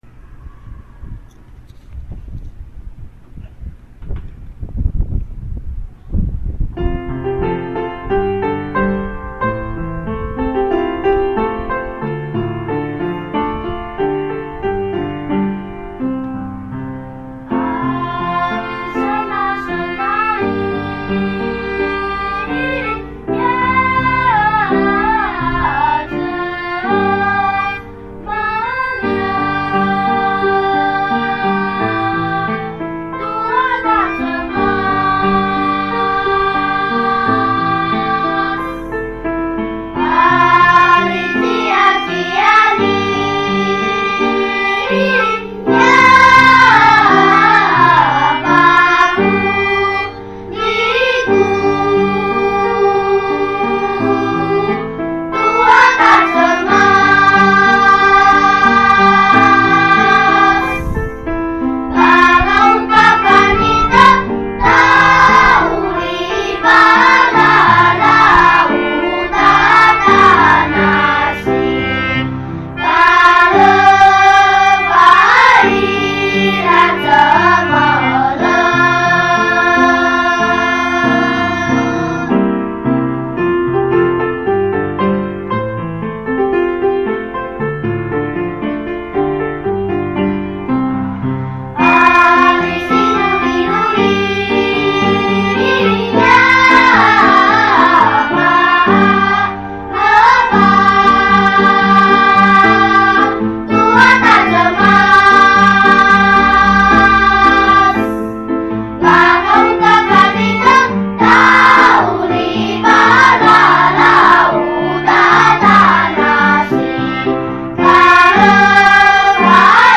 屏山原民童聲合唱團
*  102學年度成團練唱片段 共有 7 筆紀錄